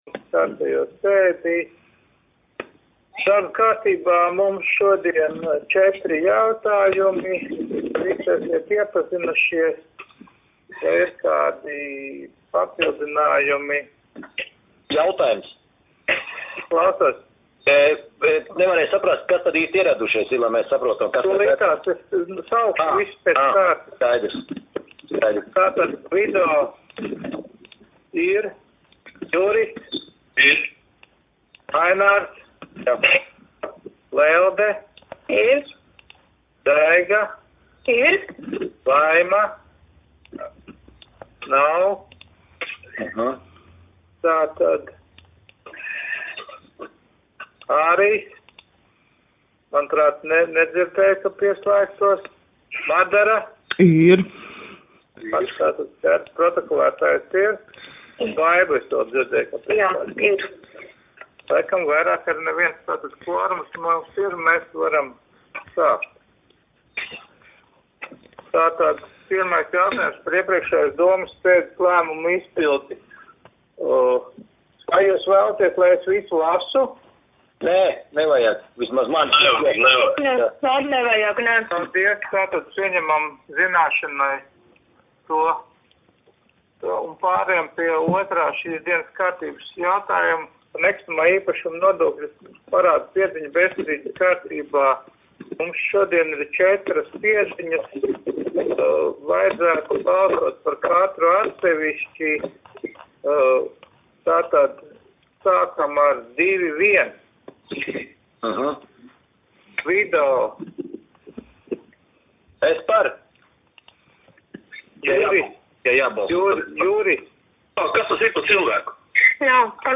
Mērsraga novada domes sēde 21.04.2020.